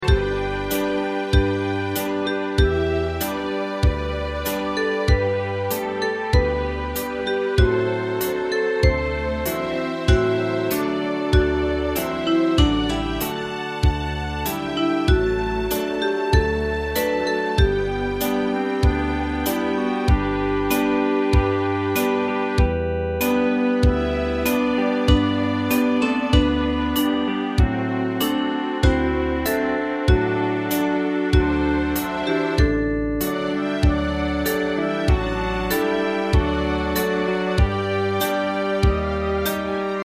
大正琴の「楽譜、練習用の音」データのセットをダウンロードで『すぐに』お届け！
カテゴリー: アンサンブル（合奏） . タグ: ポピュラー